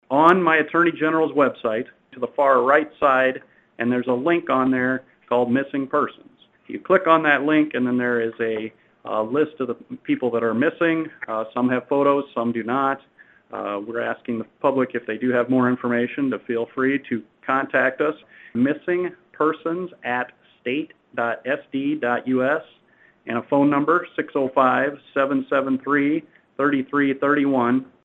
Attorney General Jason Ravnsborg says there’s a Missing Persons category on their website.